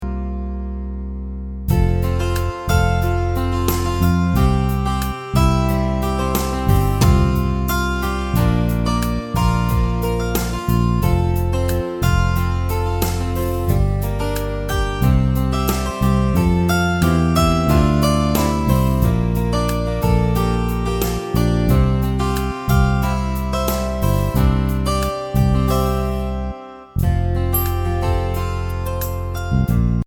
Instrumental mp3 Track